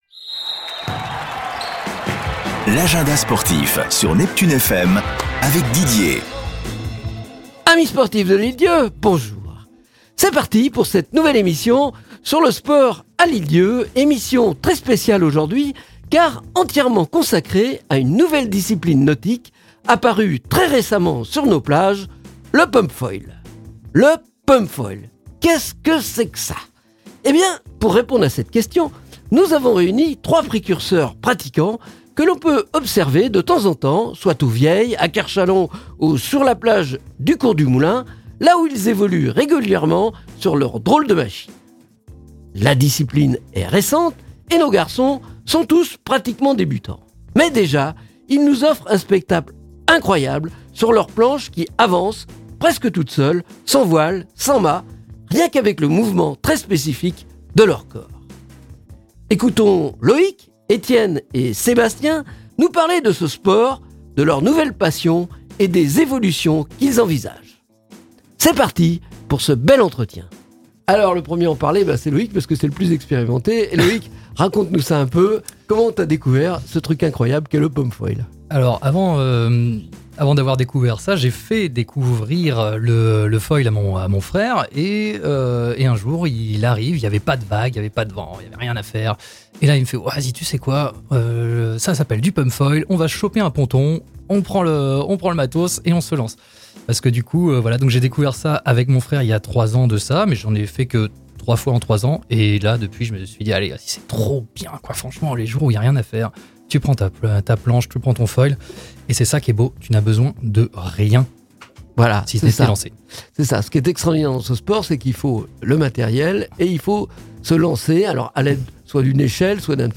Emission très spéciale aujourd'hui, car entièrement consacrée à une nouvelle discipline nautique apparue très récemment sur nos plages de l'Ile d'Yeu : le Pump Foil !